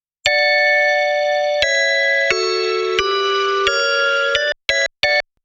その他素材(Bell Pad)試聴
Neve 1073LB使用後